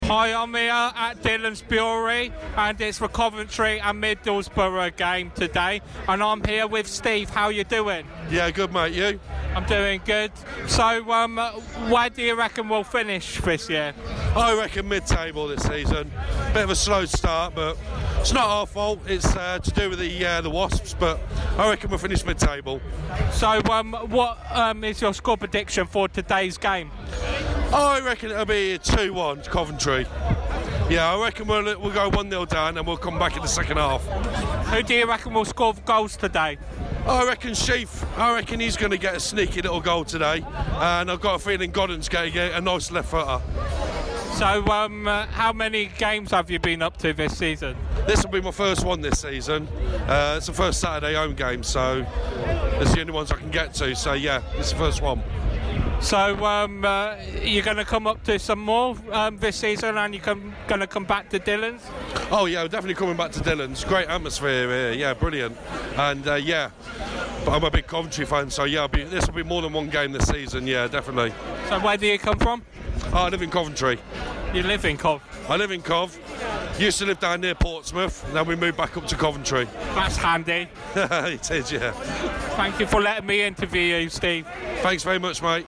Interview
Coventry & Middlesbrough game At Dhillons Brewery